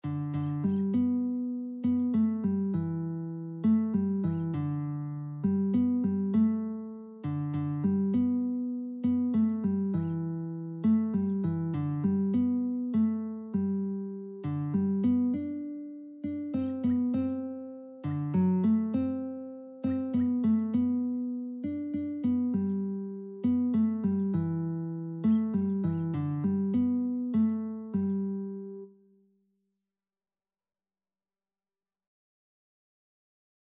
Christian Christian Lead Sheets Sheet Music Higher Ground
G major (Sounding Pitch) (View more G major Music for Lead Sheets )
3/4 (View more 3/4 Music)
Classical (View more Classical Lead Sheets Music)